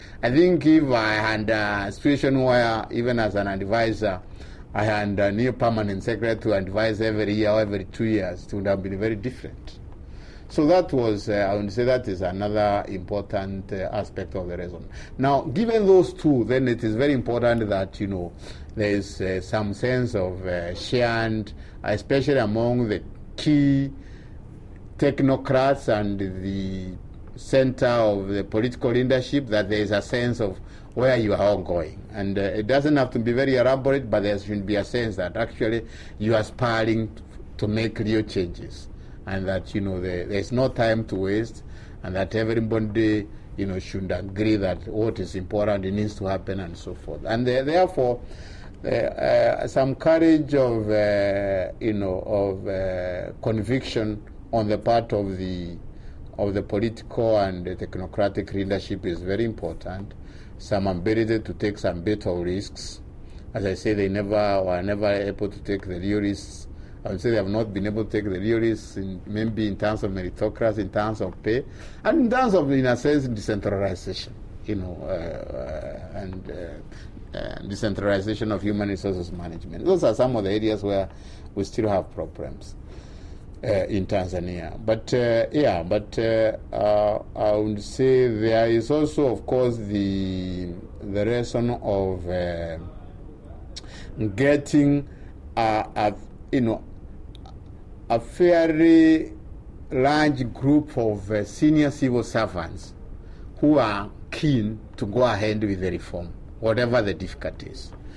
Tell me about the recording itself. World Bank